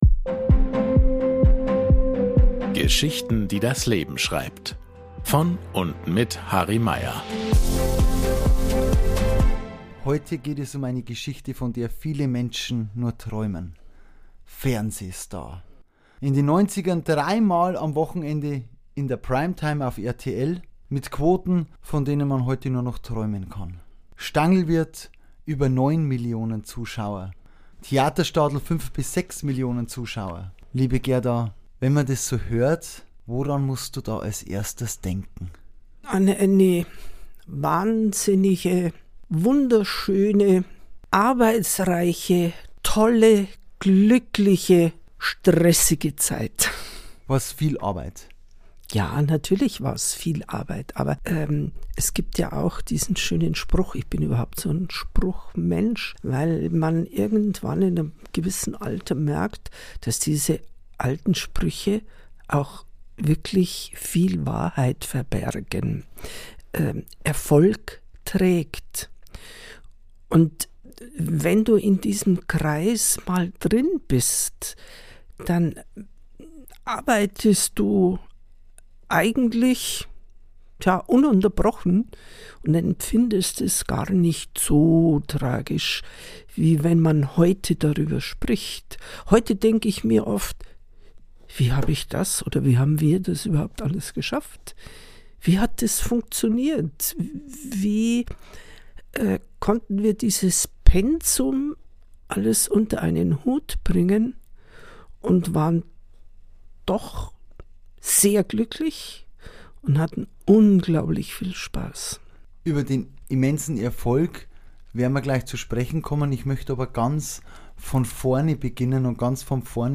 Beschreibung vor 2 Jahren In den 90gern mit der Heimatmelodie, dem Theaterstadel und dem Stangelwirt, 3mal in der Primetime bei RTL Plus mit Einschaltquoten von über 9 Millionen Zuschauer. Gerda Steiner hat, mit Ihrem Vater Peter Steiner, alles erreicht. Im Gespräch